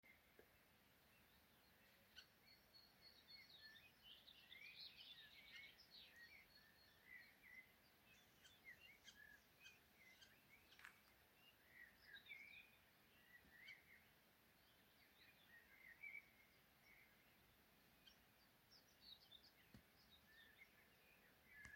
Brūnā čakste, Lanius collurio